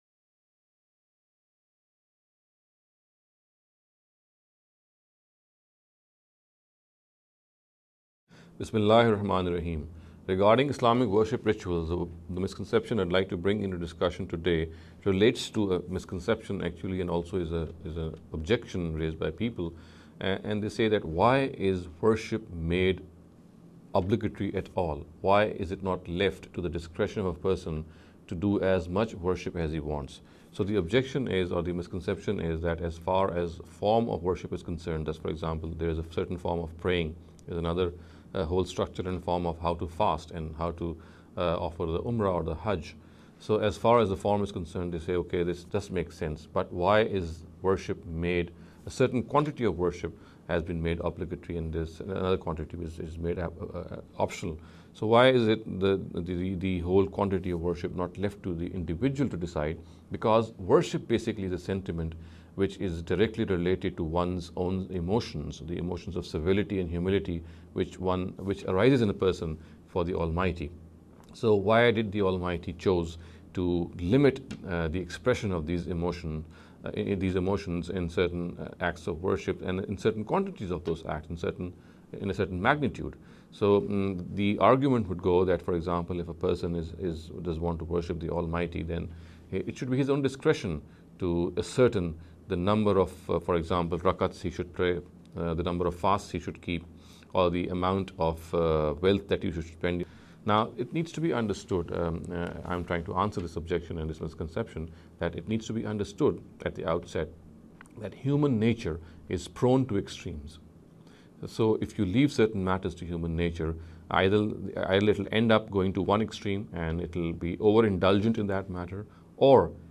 This lecture series will deal with some misconception regarding Islamic worship rituals. In every lecture he will be dealing with a question in a short and very concise manner. This sitting is an attempt to deal with the question 'Why is Worship Obligatory?'.